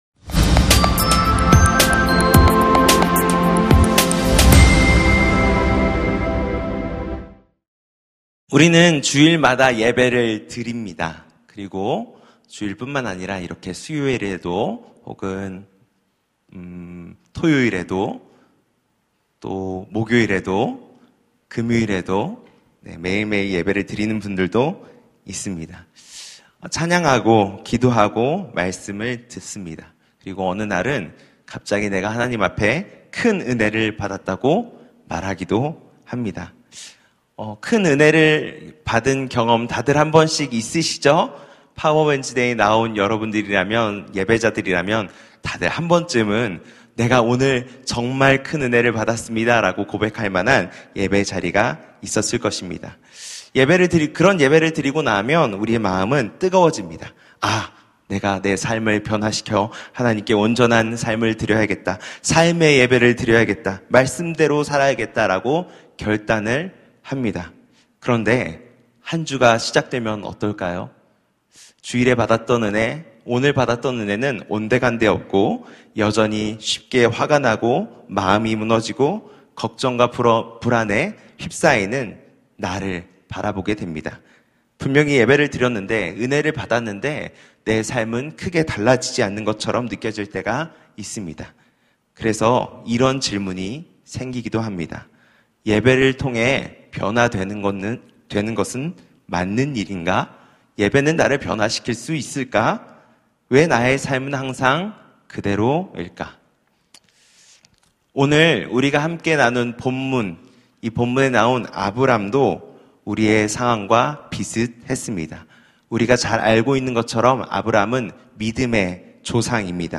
결국, 예배 > 예배와 말씀